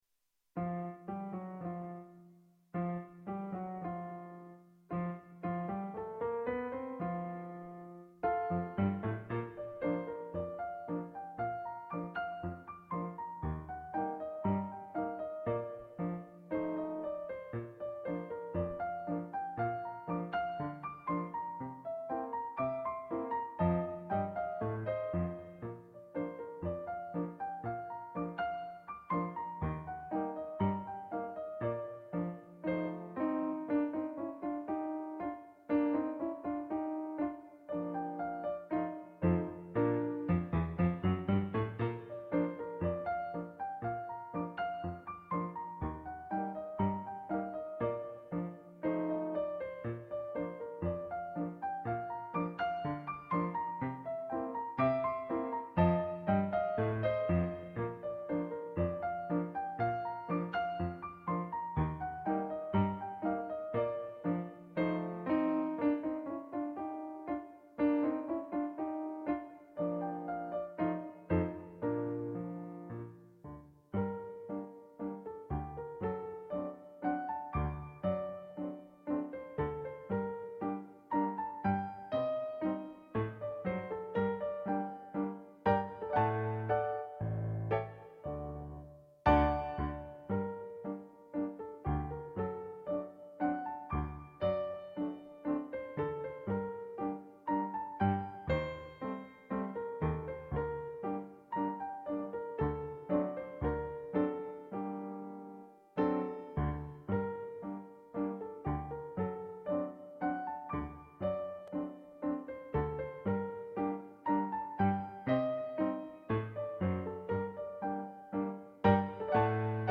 Ragtime
Si tratta ovviamente di esecuzioni fatte da un dilettante autodidatta quale sono io, ma animato da molta, moltissima passione per questo genere di musica.